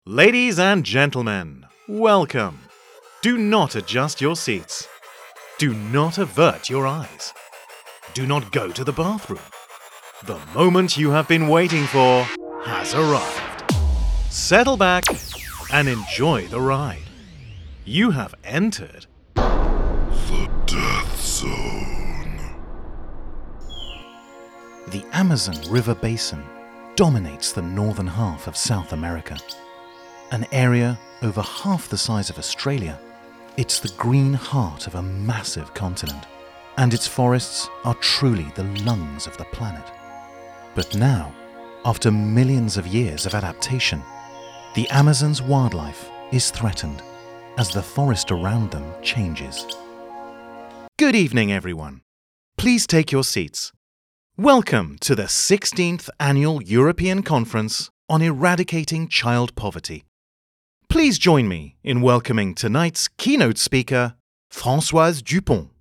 British male voiceover artist with extensive experience in animation, explainers, commercials, eLearning, documentaries and more.
Sprechprobe: Sonstiges (Muttersprache):
Clients have described my voice variously as 'soothing', 'persuasive', 'confident', 'engaging', 'the right level of playful' and with 'crystal clear enunciation'.